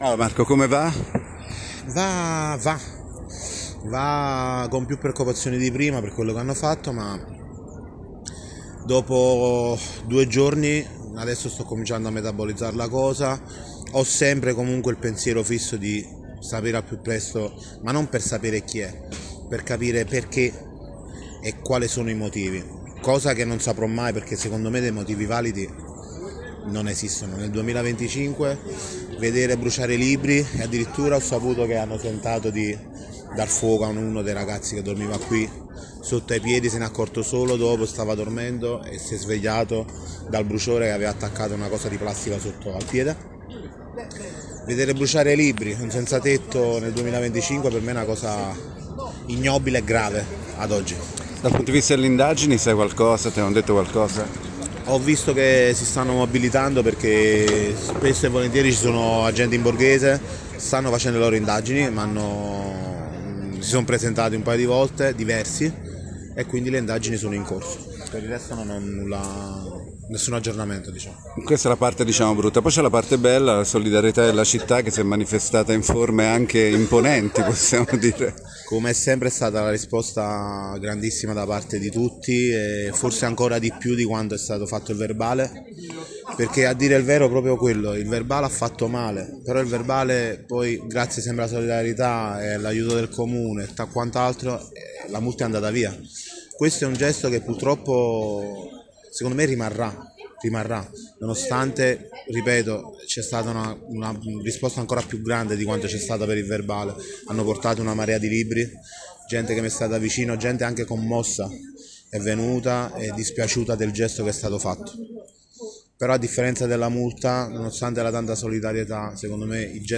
intervistare